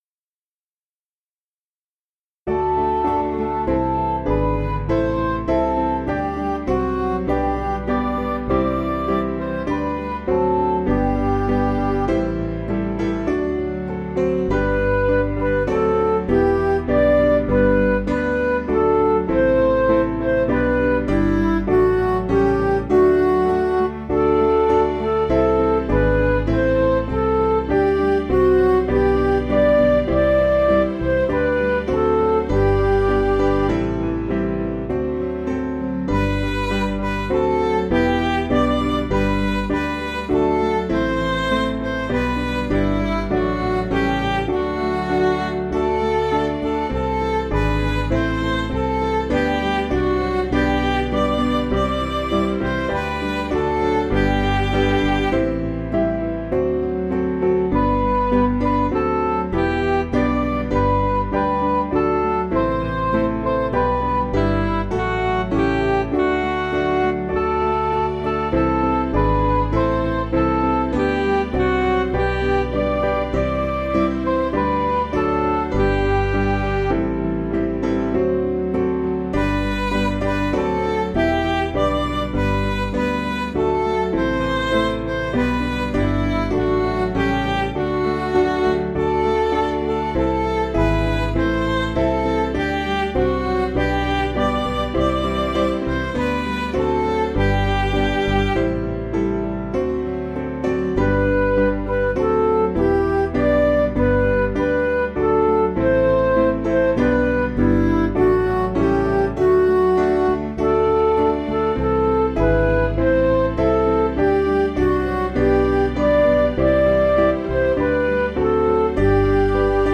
Small Band
(CM)   6/Gb-G 476.4kb